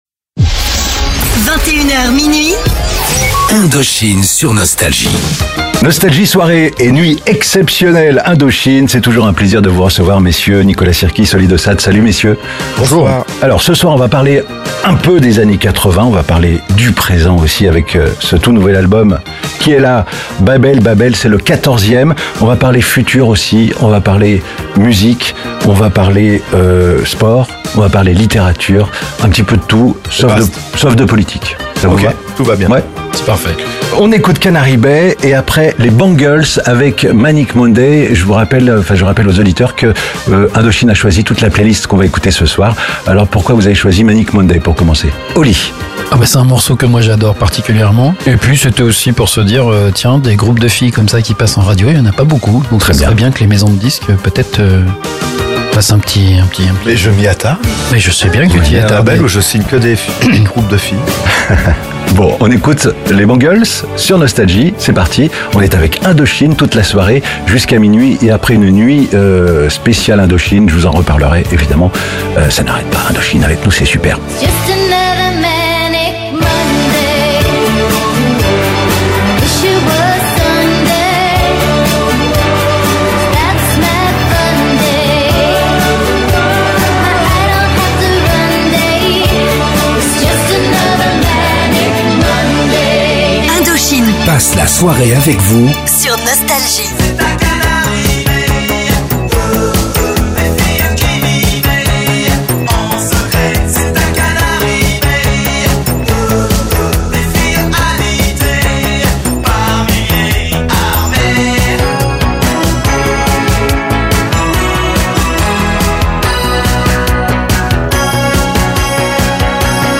Nicola Sirkis et oLi dE SaT étaient les invités exceptionnels de Nostalgie pour présenter leur nouvel album tant attendu "Babel Babel" et leur prochaine tournée.